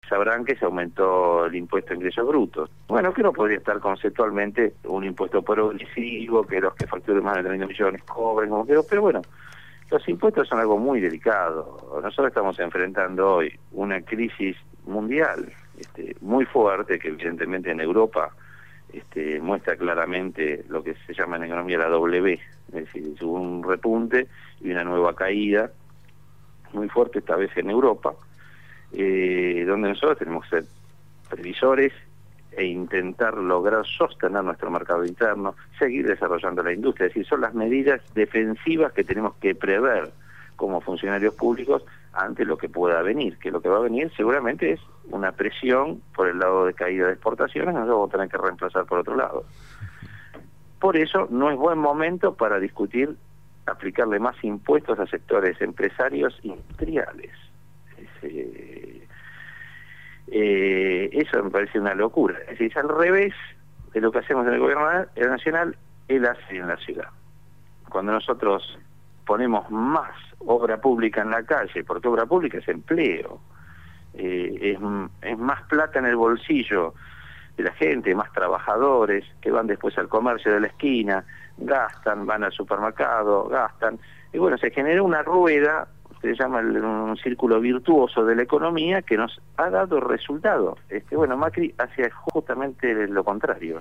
En «Punto de Partida» (Lunes a Jueves, de 08:00 a 10:00 hs) entrevistaron a Oscar Leguizamón, Secretario de Política Económica del Ministerio de Economía y Finanzas Públicas.